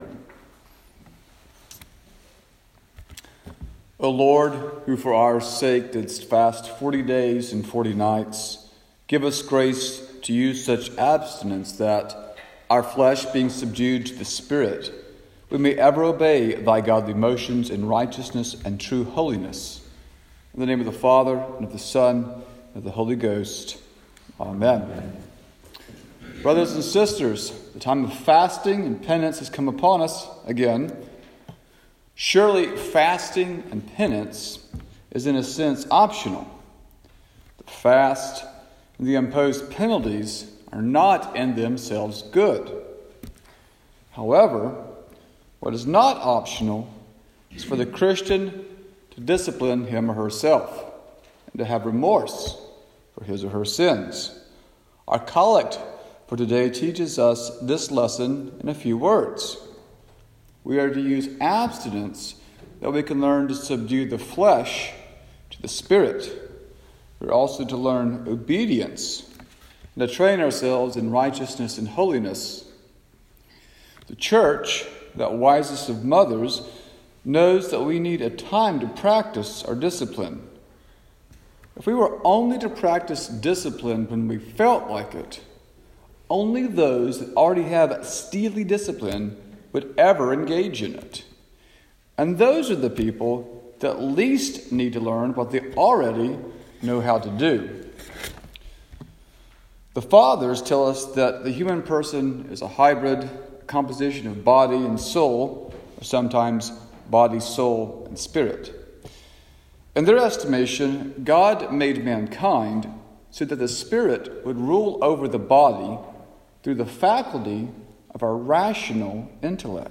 Saint George Sermons Sermon for Lent 1